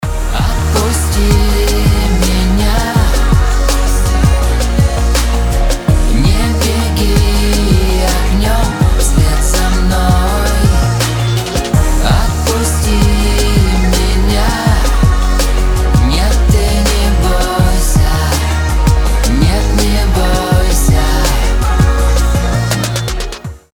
поп
громкие
Хип-хоп